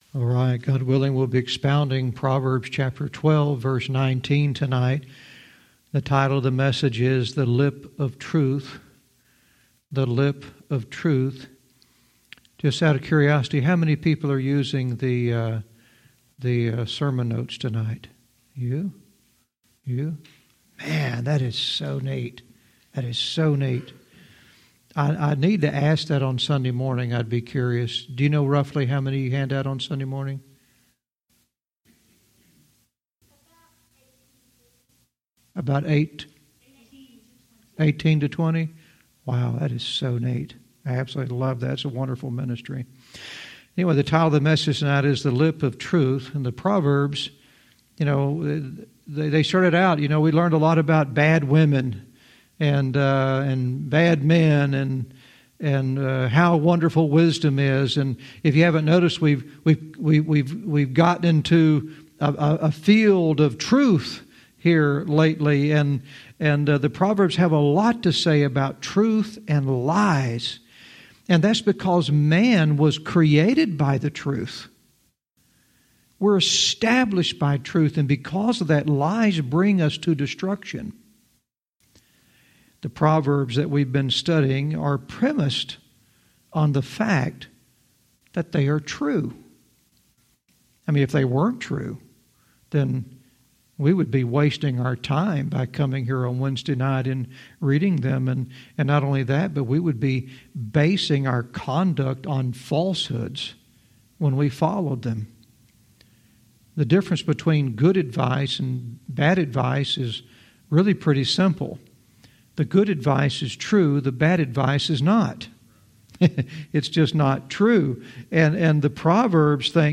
Verse by verse teaching - Proverbs 12:19 "The Lip of Truth"